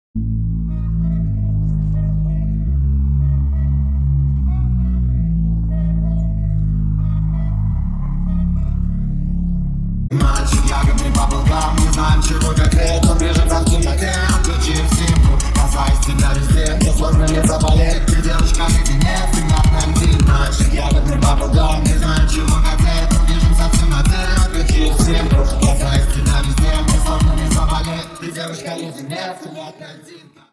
• Качество: 320, Stereo
поп
мужской голос
веселые